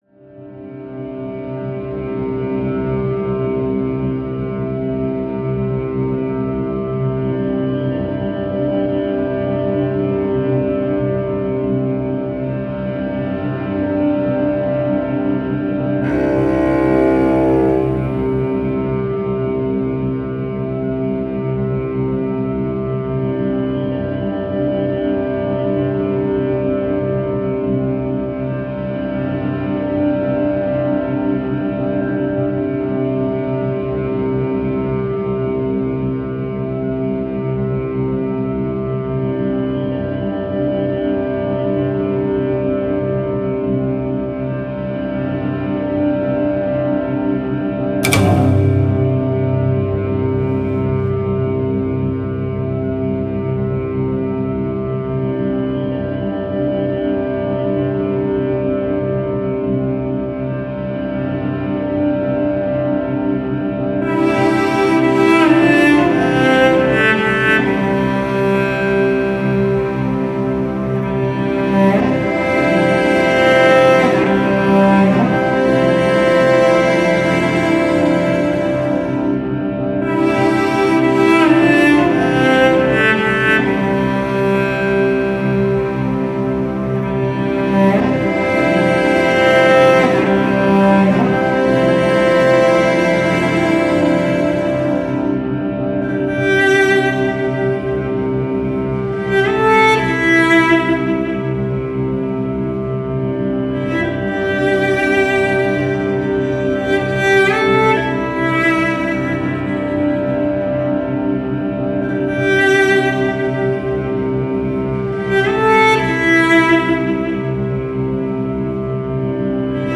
Classical, Soundtrack, Emotive, Sad, Strings